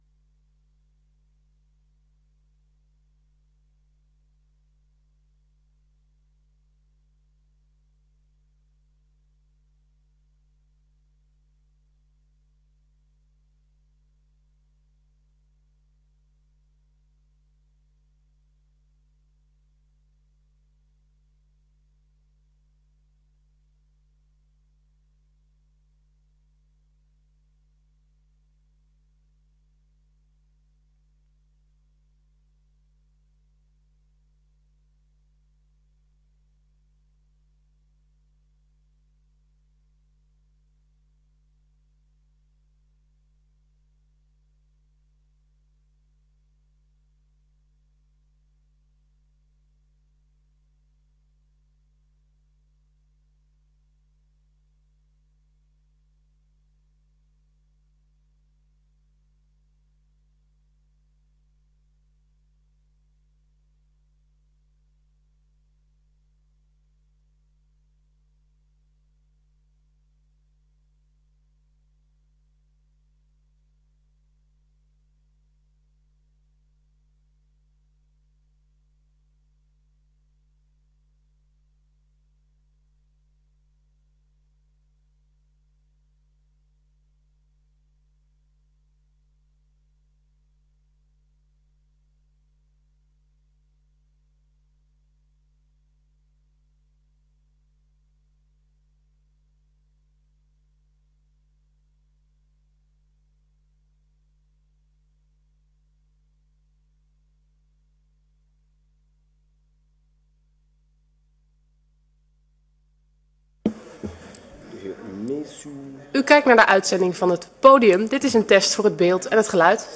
Gemeenteraadsleden mogen in deze tijd ook vragen aan u stellen.